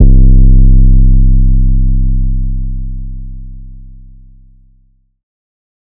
Metro Shy 808 (C).wav